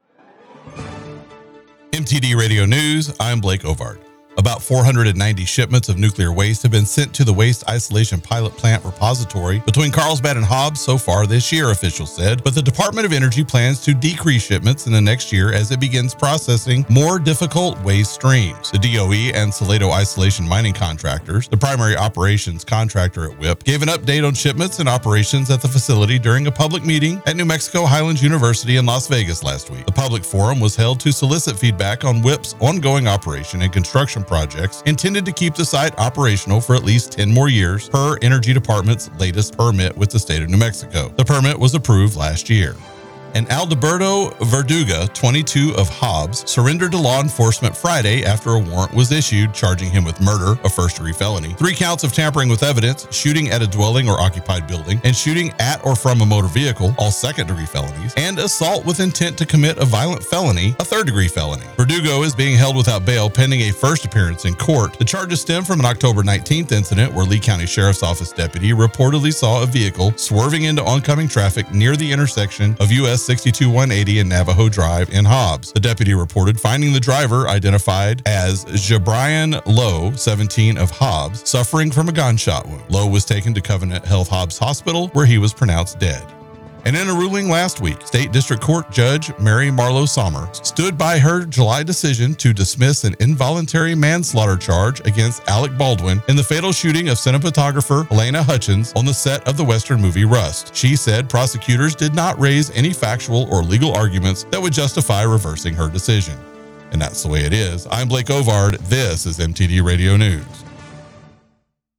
W105 NEWS SE NEW MEXICO AND WEST TEXAS